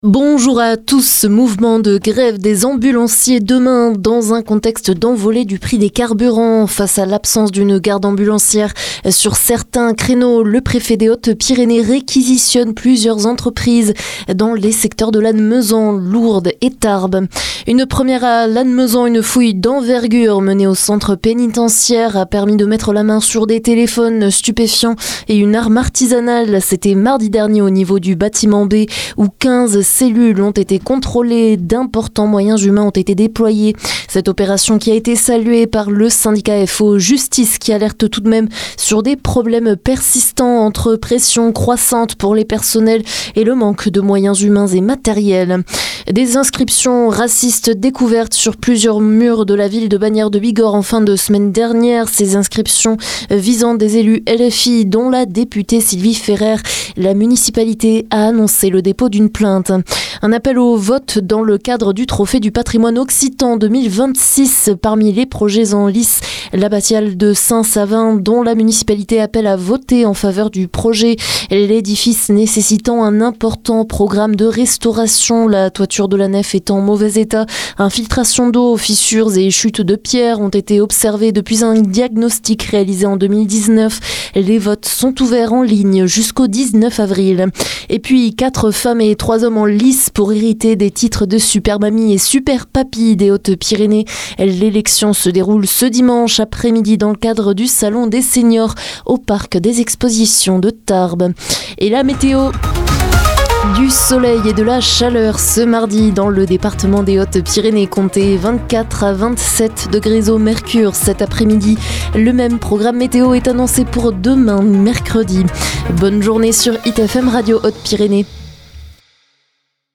Flash HPY 07 avril midi